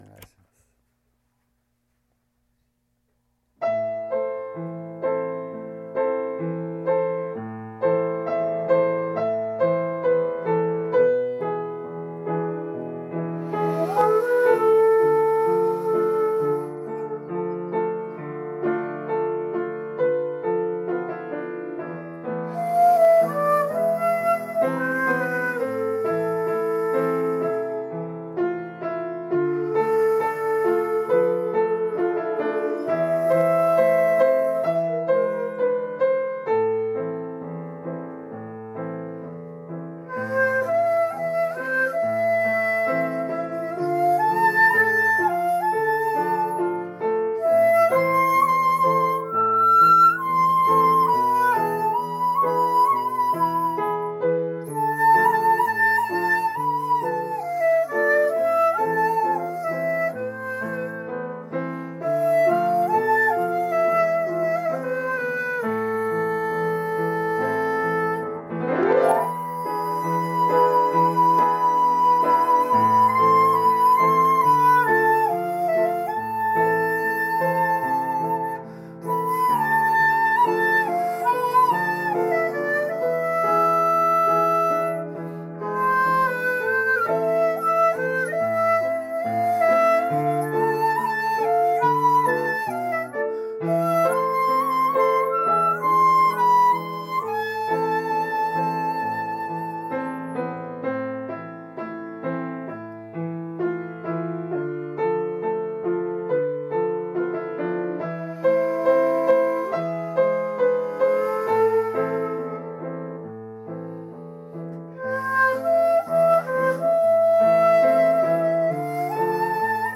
尺八とキーボードで歌謡曲などを演奏するのですが、キーボード奏者は楽器の運搬・設定を略すためにお稽古場のピアノで伴奏してもらいました。